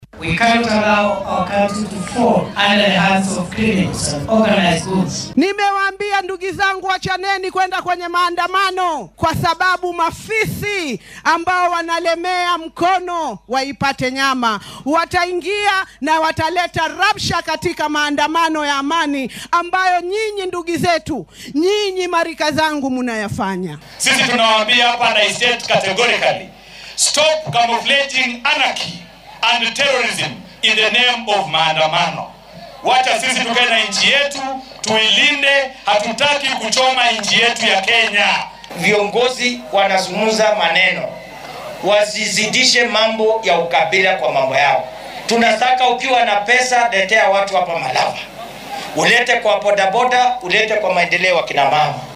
Madaxweyne ku xigeenka dalka Prof. Kithure Kindiki ayaa mar kale wacad ku maray in tallaabo adag laga qaadi doono qorshayaashii iyo kuwii ka dambeeyay qalalaasaha ka dhacay dalka Arbacadii, taasi oo keentay in ganacsatada ay xisaabiyaan khasaaraha gaarayo boqolaal milyan oo shillin. Isagoo ka hadlayay Kitui Sabtidii shalay, Kindiki ayaa waxaa uu sheegay in iyada oo dowladda ay ixtiraamayso xaqa dibadbaxa, aysan oggolaan doonin in lagu xad-gudbo xuquuqaha kale sida burburinta hantida.